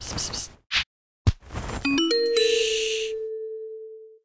Store Weapon Buy Sound Effect
store-weapon-buy.mp3